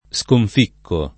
sconficcare v.; sconficco [